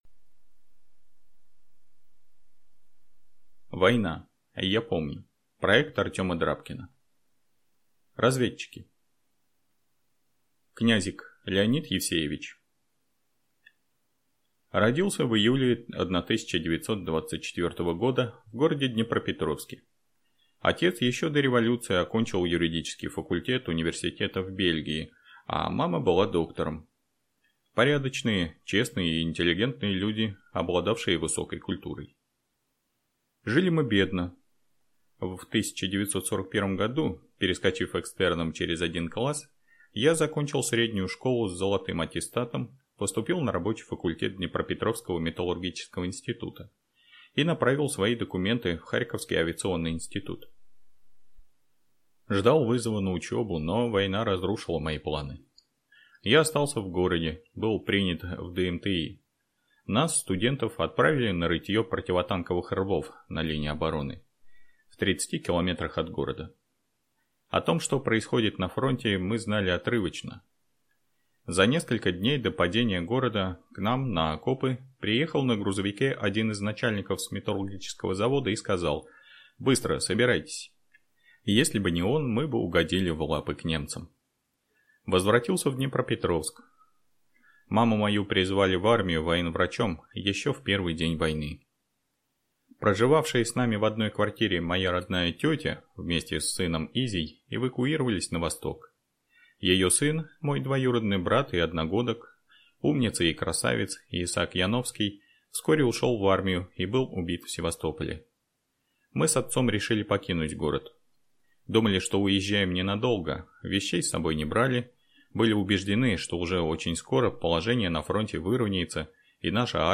Аудиокнига Разведчики | Библиотека аудиокниг